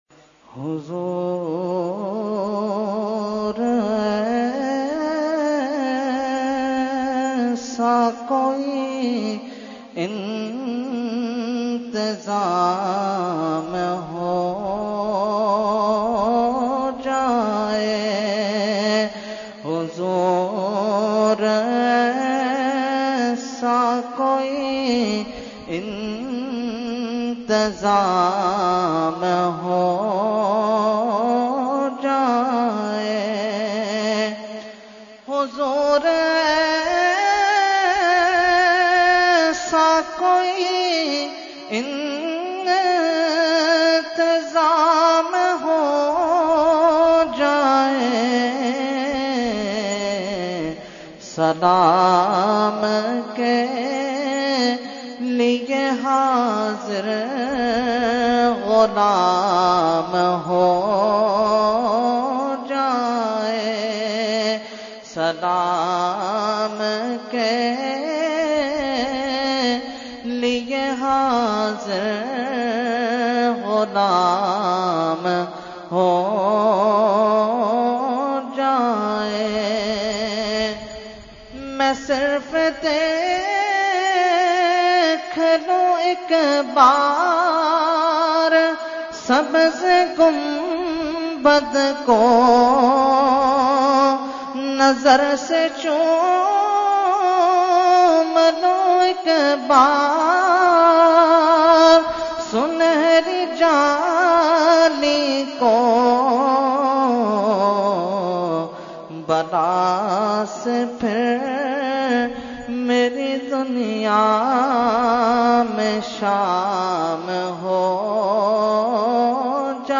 Category : Naat | Language : UrduEvent : Khatmul Quran 2018